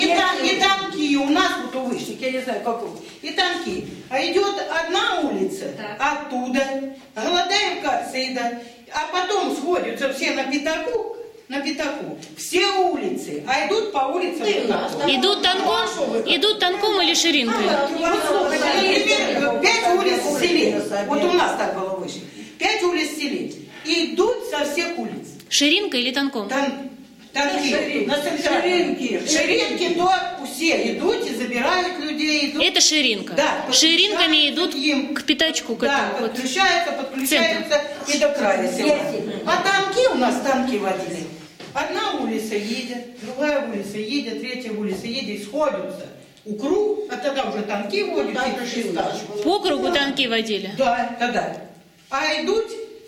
Репортаж о танках и ширинках в селе Вышние Пены Ракитянского района Белгородской области
Место фиксации: Белгородская область, Ракитянский район, сёло Вышние Пены